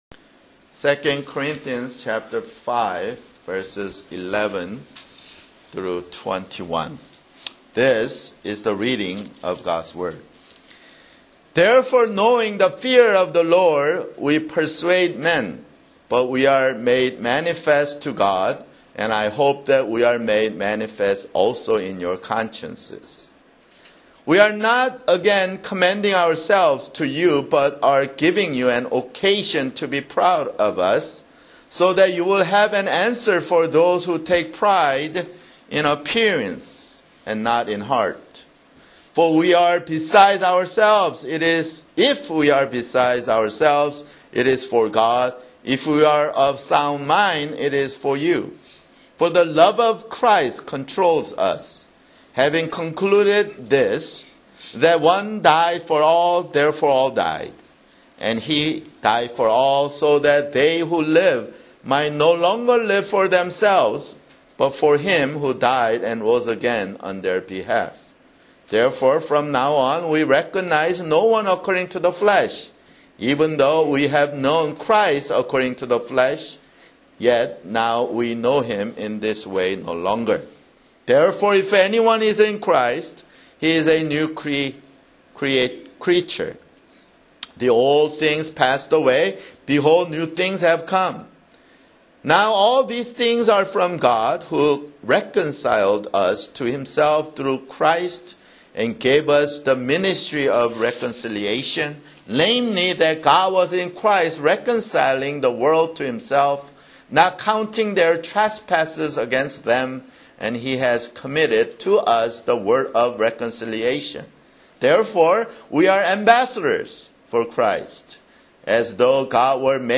[Sermon] 2 Corinthians (28)